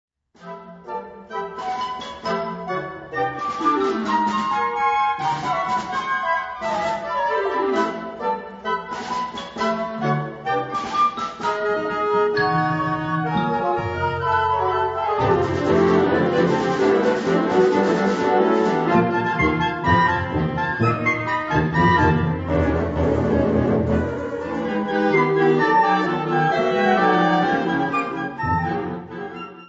Kategorie Blasorchester/HaFaBra
Unterkategorie Zeitgenössische Musik (1945-heute)